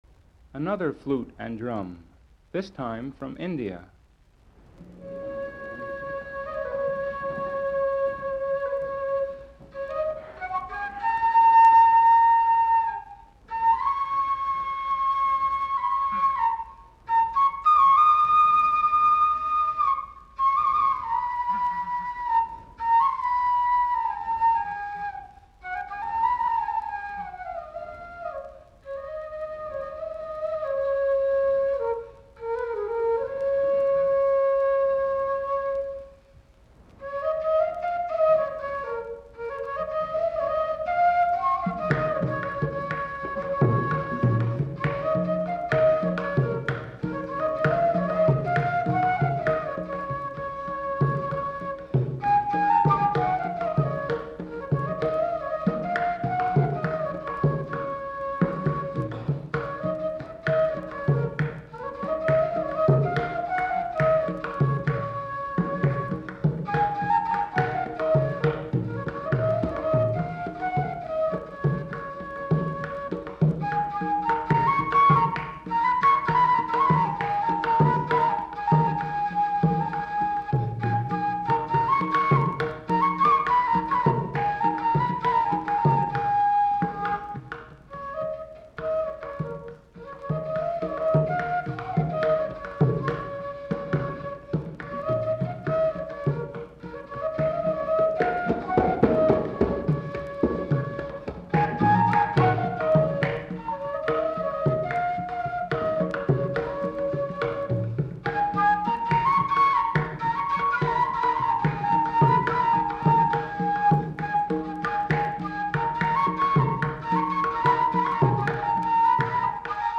18. A flute and drum from India and closing comments